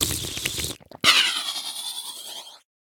drink.ogg